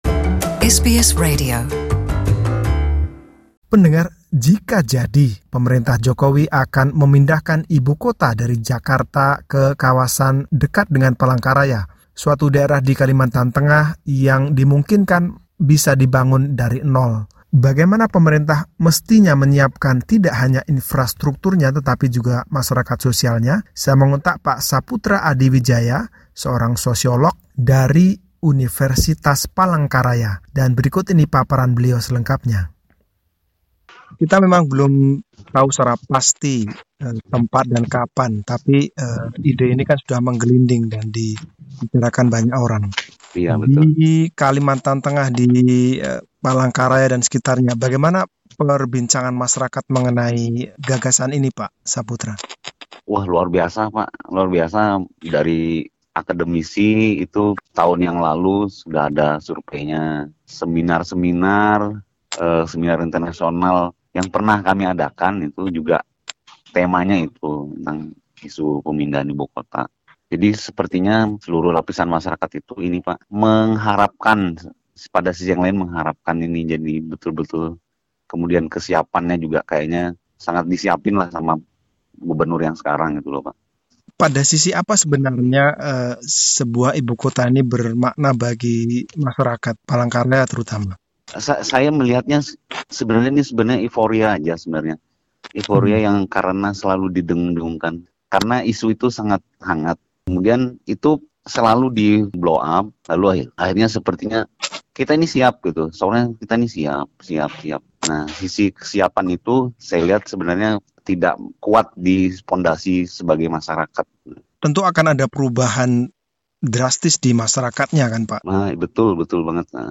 Perbincangan